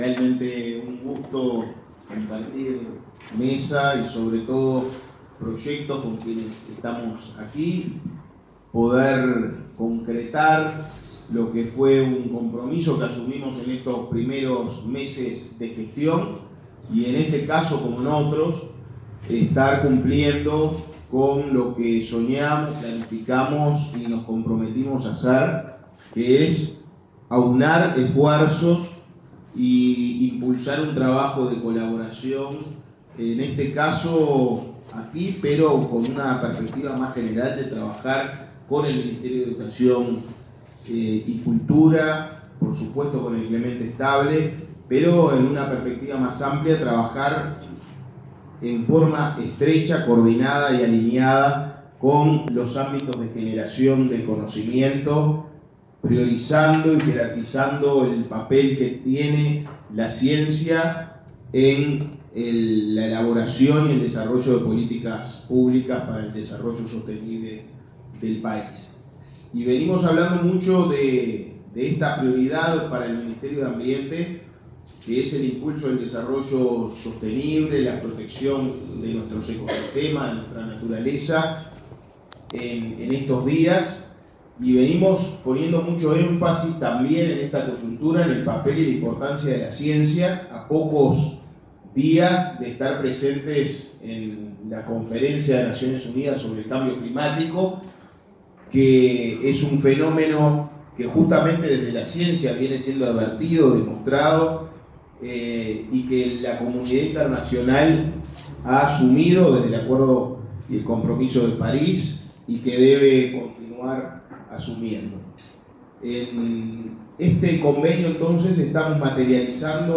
Palabras de los ministros de Educación y Cultura y Ambiente